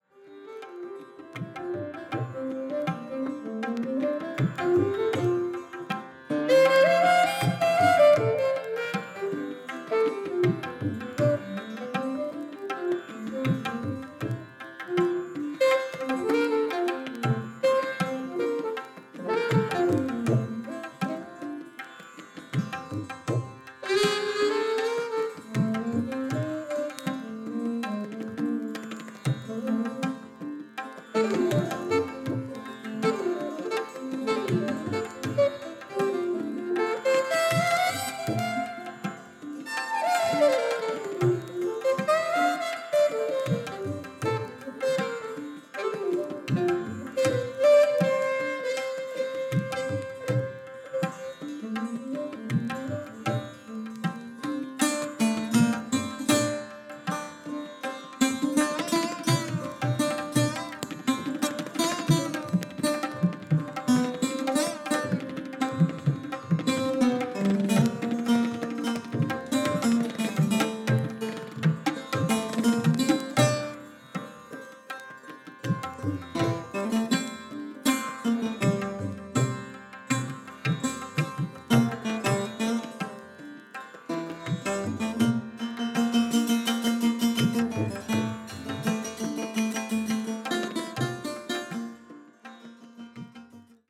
Alto Saxophone
Sarod
Tambura
Tabla